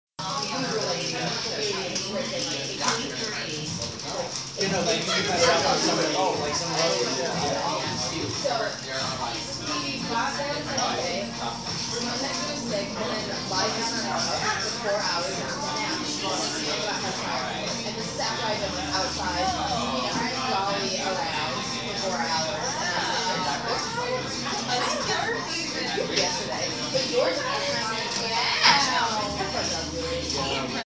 Field Recording Número Tres
Location: Au Bon Pan dining room
Sounds Featured: Misc. Conversation, Lousy Top-40 Music, clatter of plastic-ware